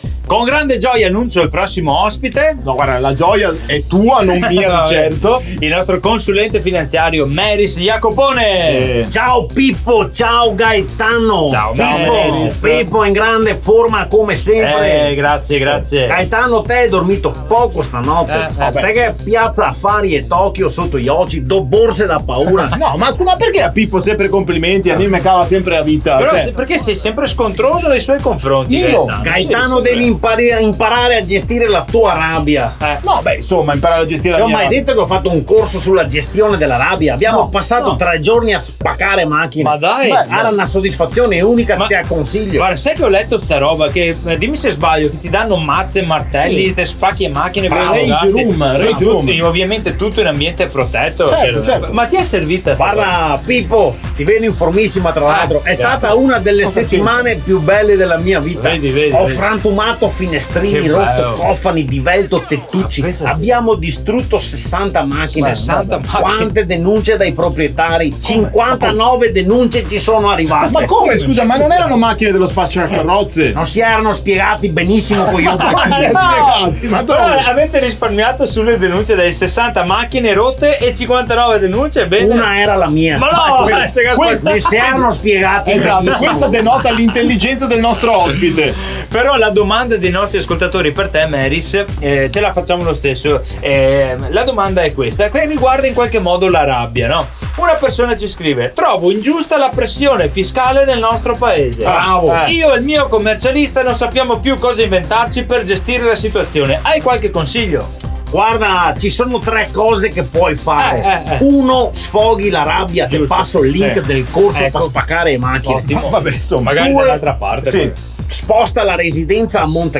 🎙 Ogni settimana, uno sketch ironico e fuori dagli schemi ha raccontato – a modo nostro – quanto può fare la differenza affidarsi a chi lavora con PROFIS.
Tra gag, colpi di scena e un consulente un po’... così, il risultato è tutto da ascoltare!